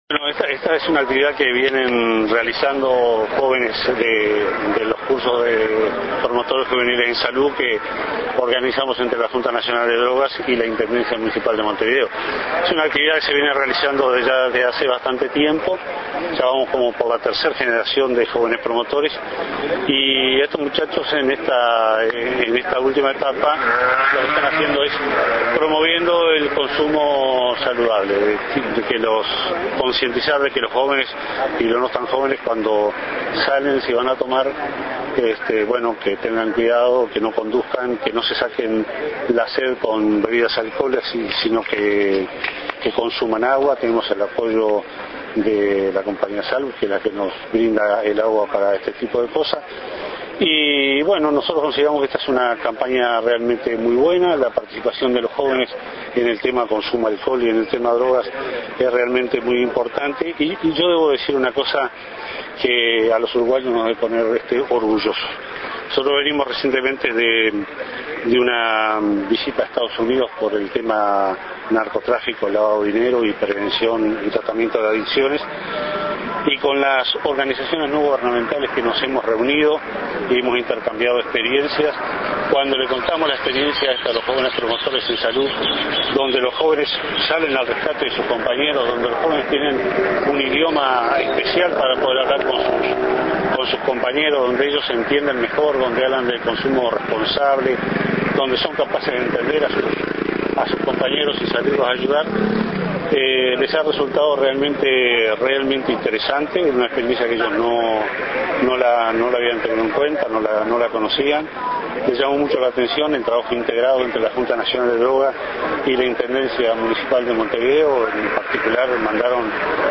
Declaraciones del Presidente y del Secretario General de la Junta Nacional de Drogas, Jorge Vázquez y Milton Romani, en el lanzamiento de la Campaña  de Reducción de Riesgos y Daños por Consumo de Alcohol: ¿Salís Hoy? Acordate, la sed sacátela con agua.